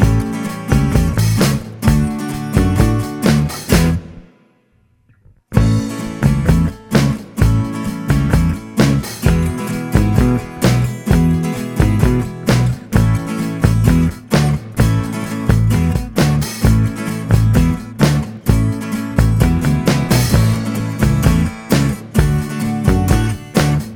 No Backing Vocals Rock 'n' Roll 2:51 Buy £1.50